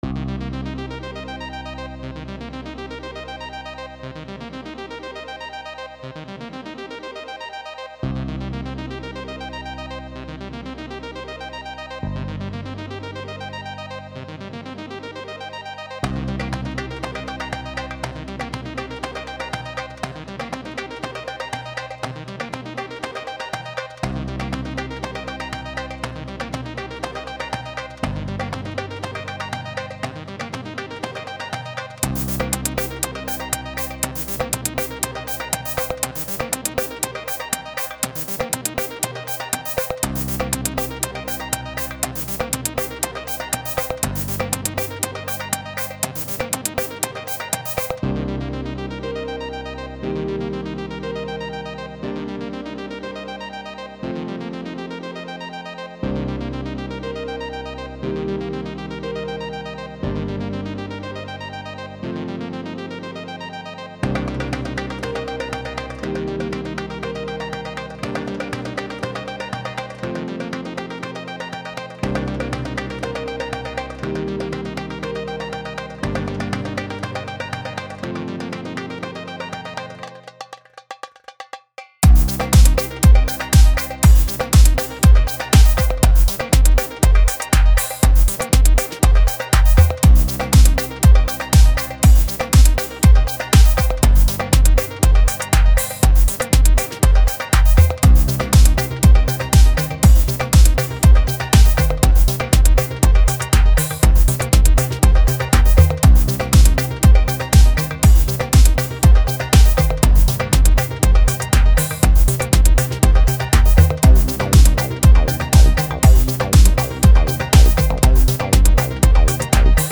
Just a simple fancy & upbeat music with darbuka instrument in it.
blow_the_day_with_a_darbuka.mp3